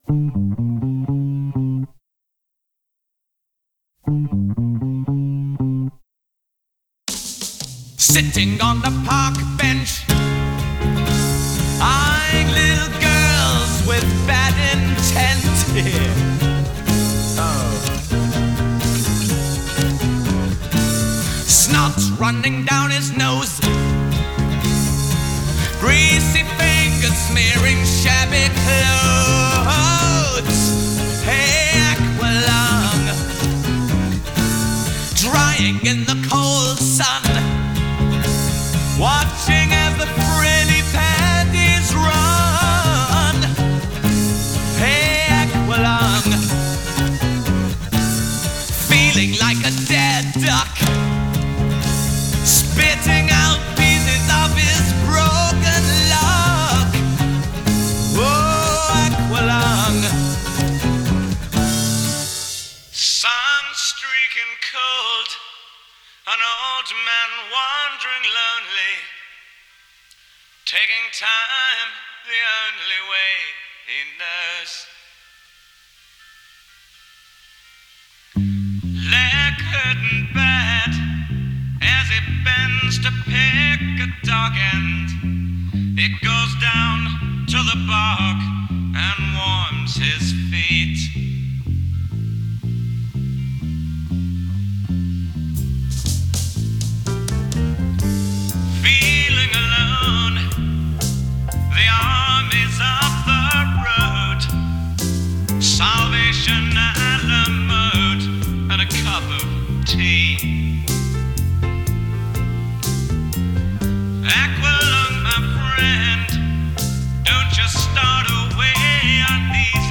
These are my mixes!!!
No Electric Guitars